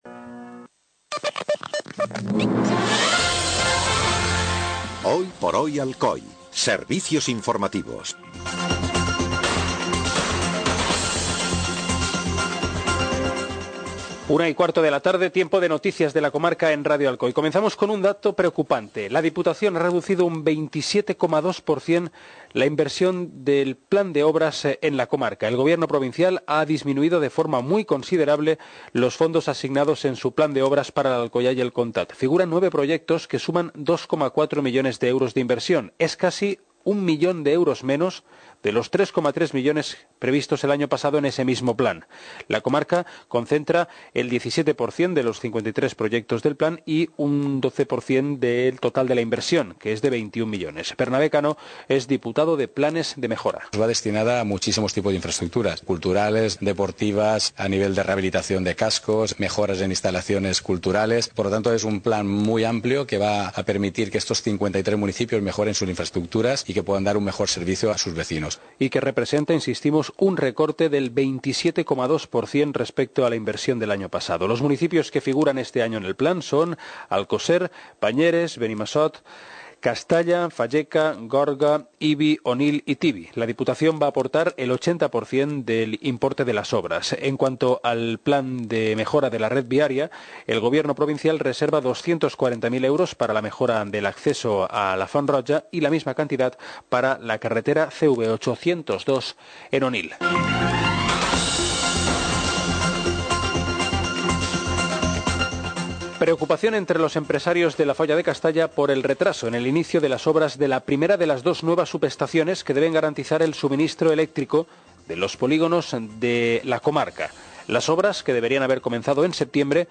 Informativo comarcal - viernes, 17 de febrero de 2017